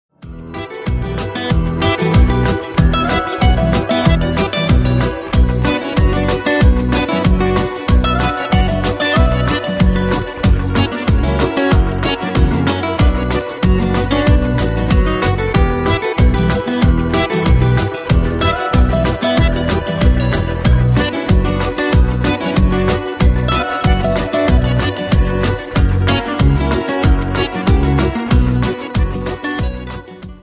Рингтоны Без Слов
Шансон